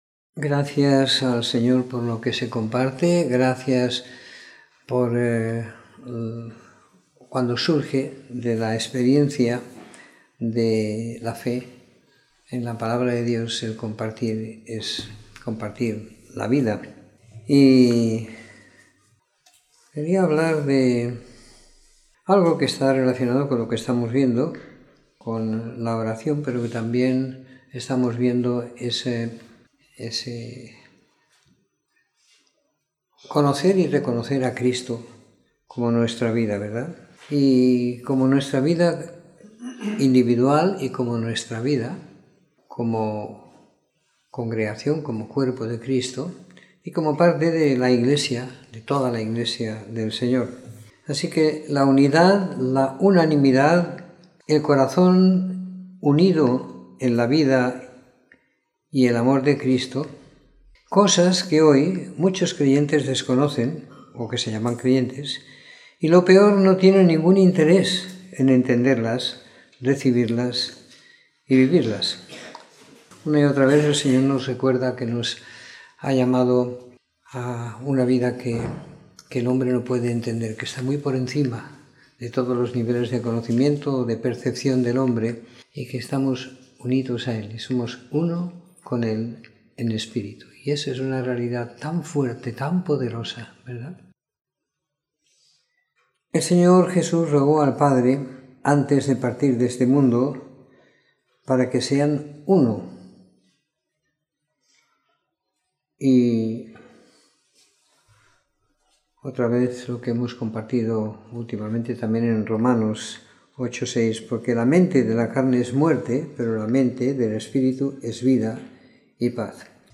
Domingo por la Tarde . 03 de Febrero de 2019